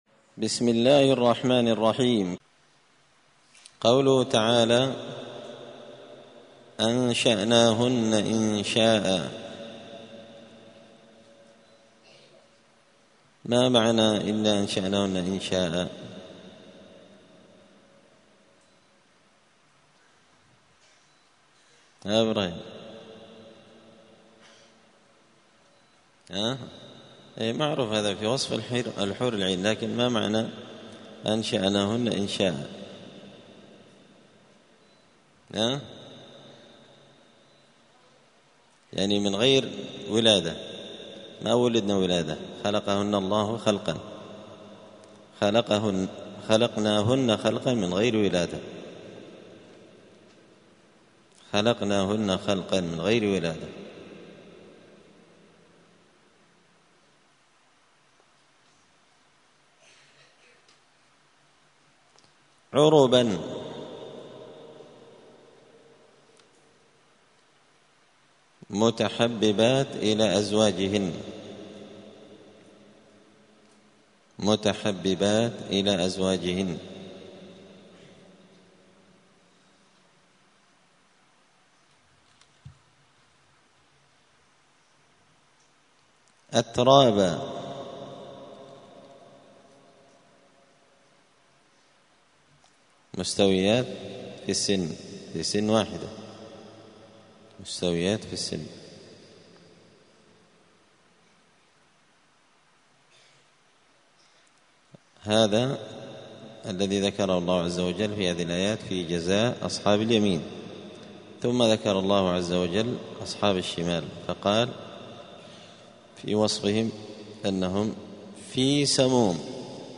الأثنين 29 صفر 1446 هــــ | الدروس، دروس القران وعلومة، زبدة الأقوال في غريب كلام المتعال | شارك بتعليقك | 23 المشاهدات